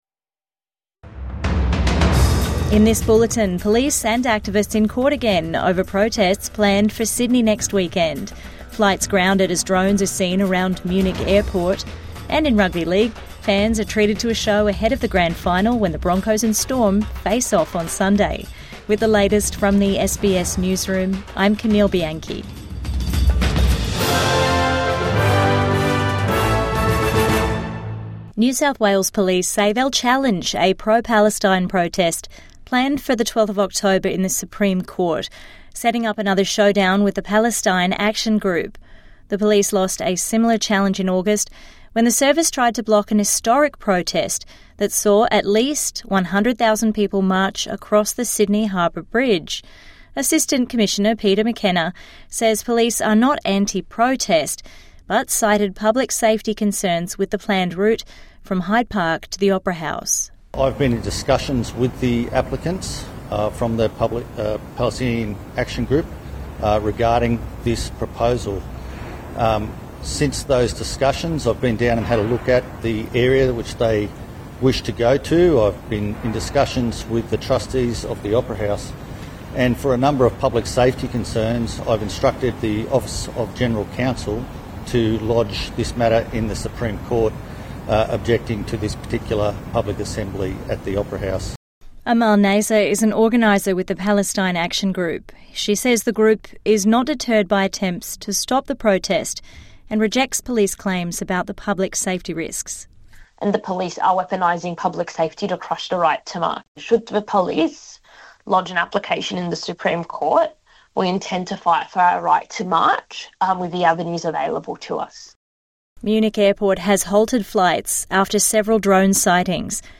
Police, protesters face court over planned march | Evening News Bulletin 3 October 2025